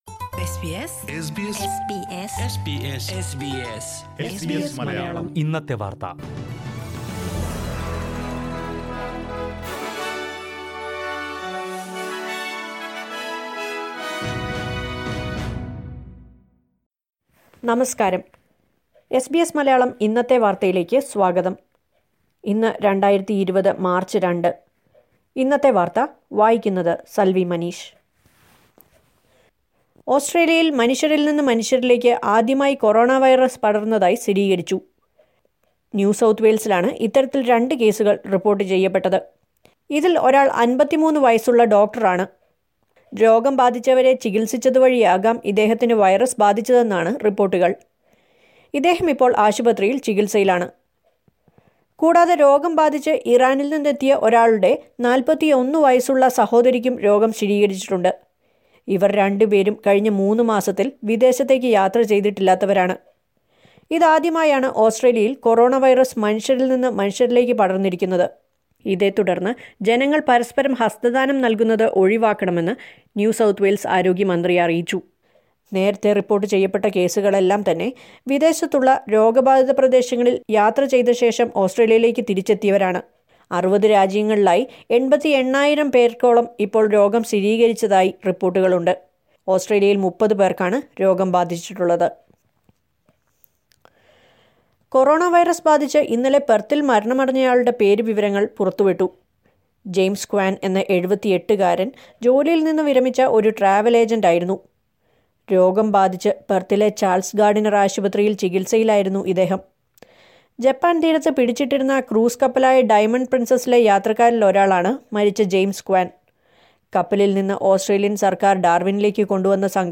2020 മാർച്ച് രണ്ടിലെ ഓസ്ട്രേലിയയിലെ ഏറ്റവും പ്രധാന വാർത്തകൾ കേൾക്കാം…
news_mar_2_0.mp3